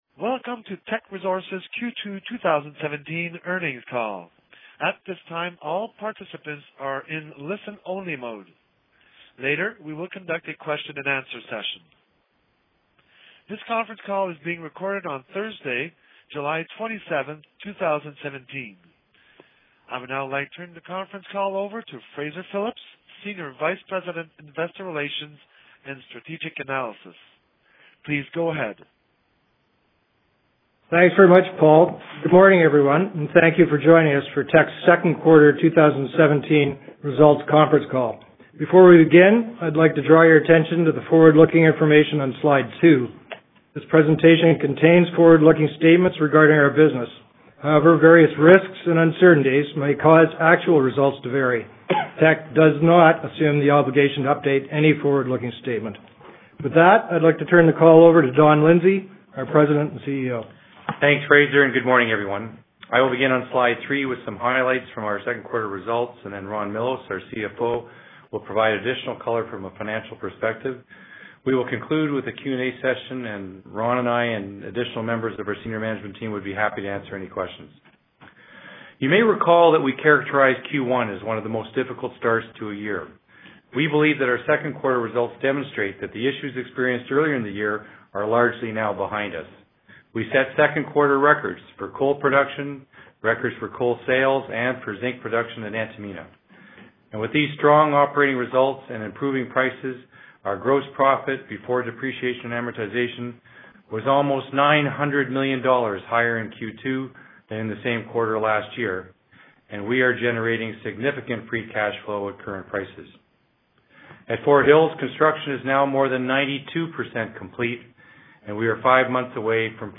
Q2-2017-Financial-Report-Conference-Call-Audio.mp3